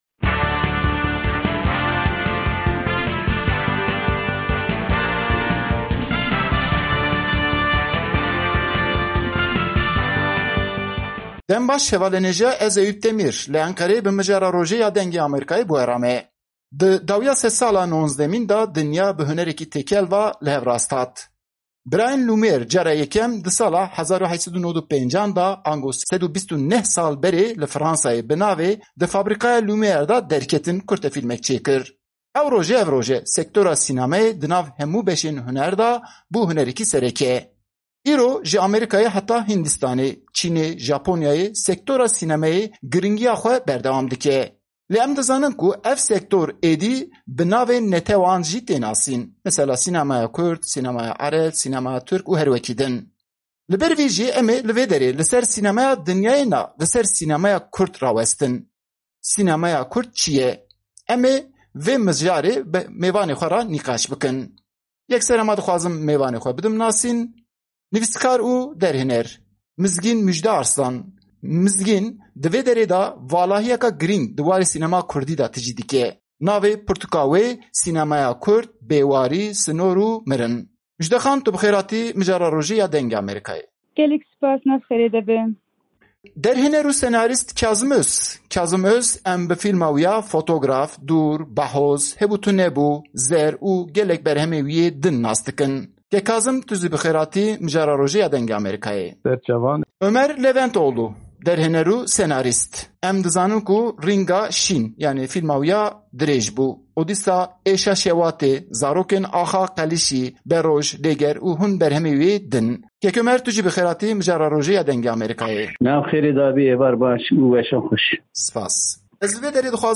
Gotûbêj Derbarê Dahatûya Sînema Kurdî.mp3